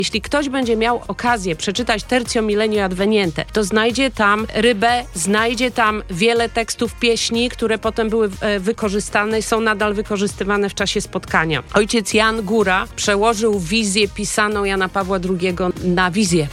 GOŚCIE BUDZIK MORNING SHOW